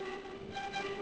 violin